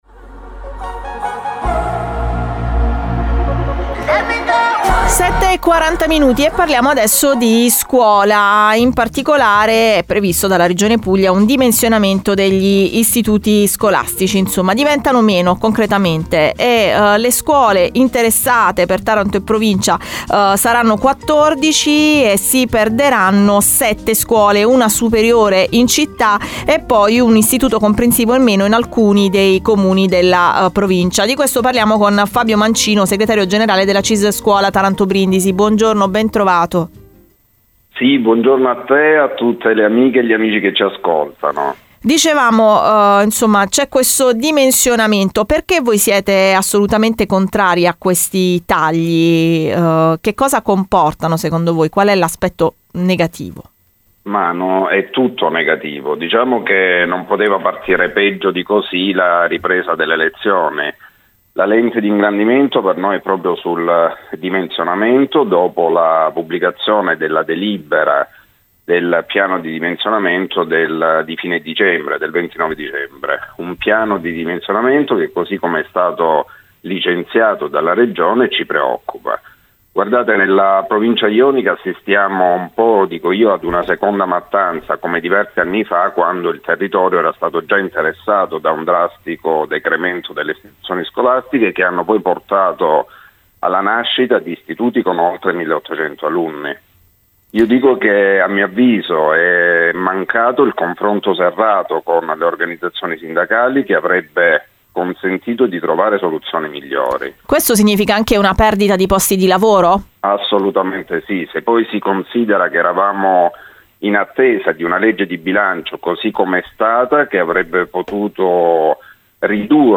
L'intervista - Radio Cittadella / 16 GEN 2024